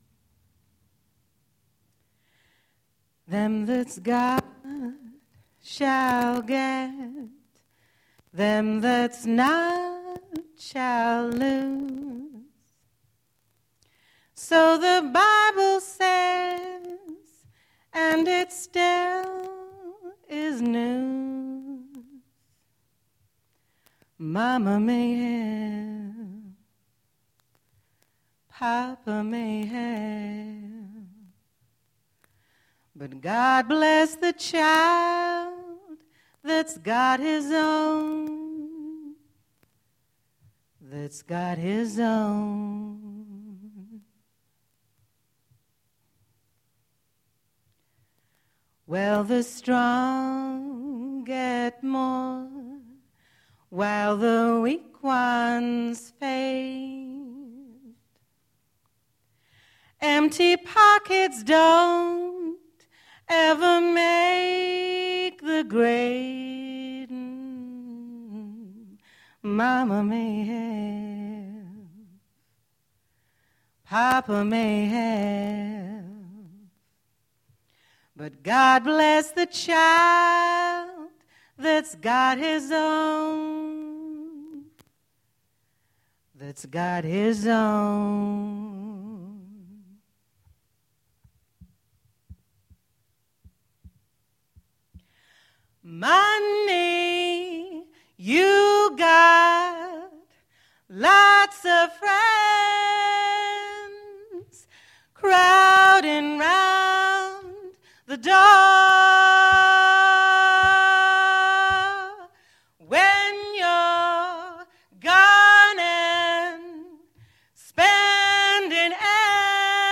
We4Poets Live at the IABF, Manchester
jazz